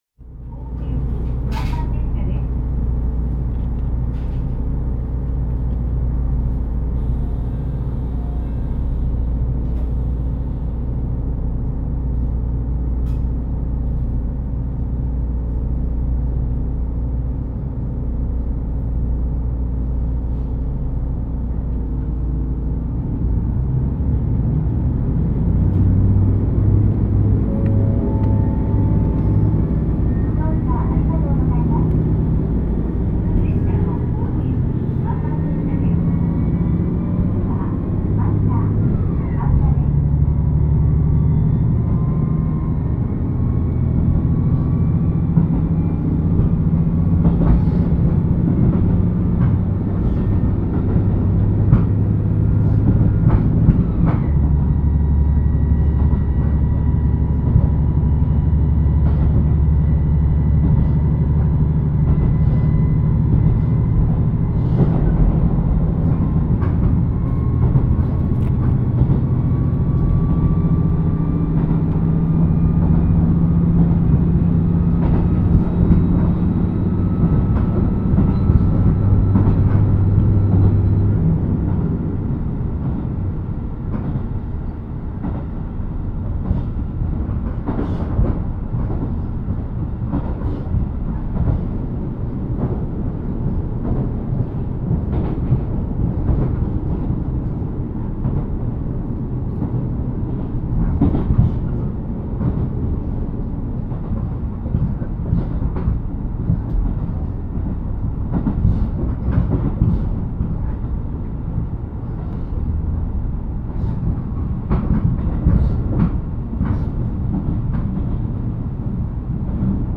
エンジンは定格520PSを発揮するカミンズ製C-DMF14HZD形ディーゼルエンジンを搭載していますが、通常は450PS以内に制限して運用し、異常時のみフルパワーを発揮します。
走行音
録音区間：東成岩～半田(お持ち帰り)